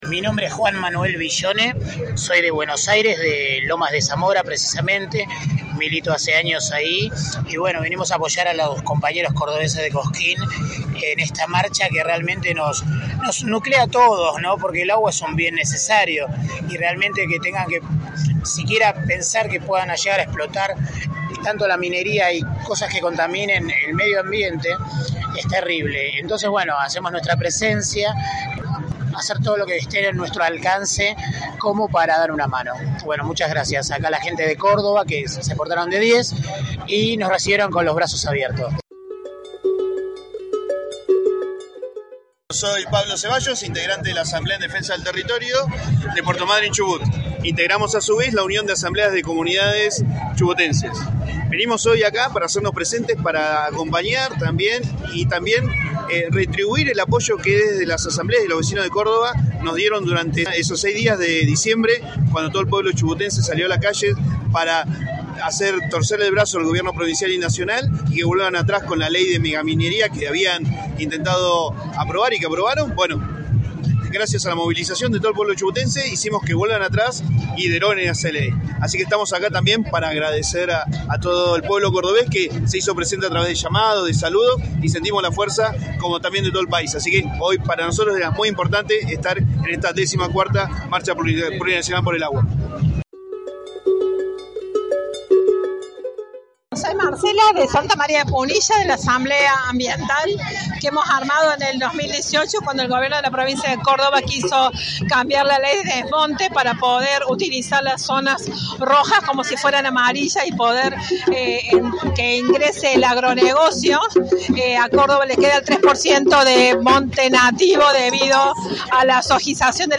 voces-en-la-14-marcha.mp3